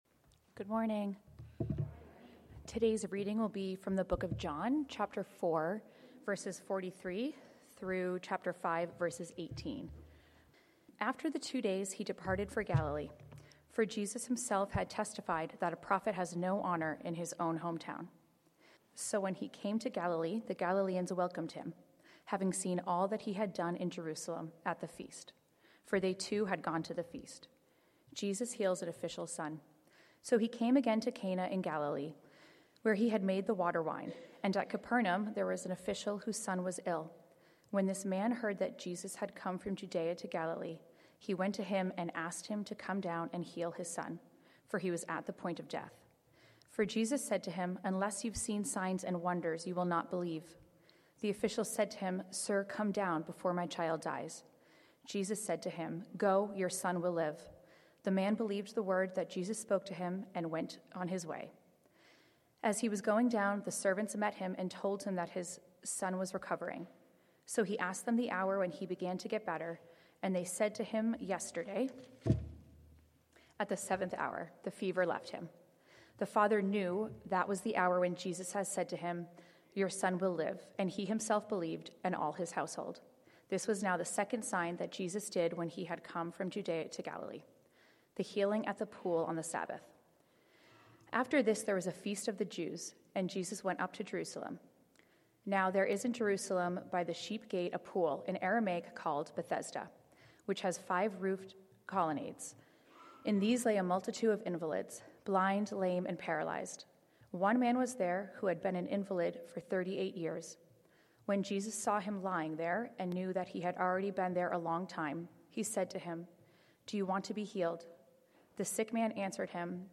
Passage: John 4:43-5:18 Sermon